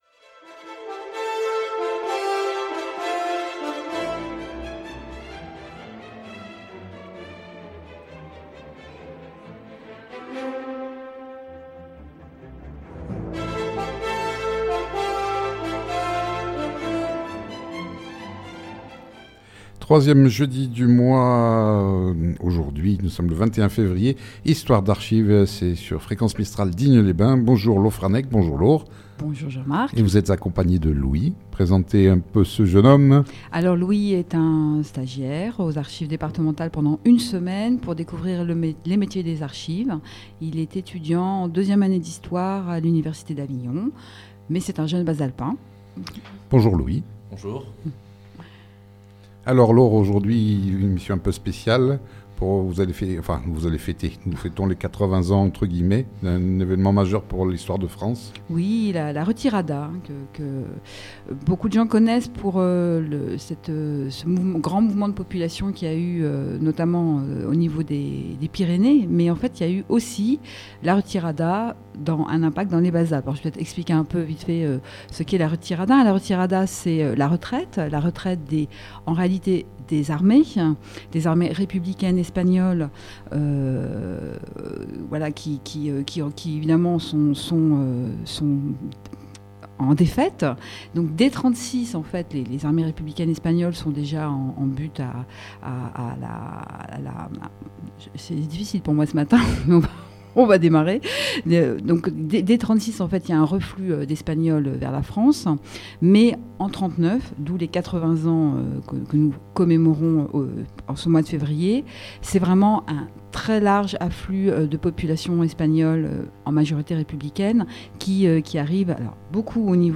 Cette émission sur fréquence mistral à Digne, a lieu tous les 3èmes jeudi du mois en direct de 9h15 à 10h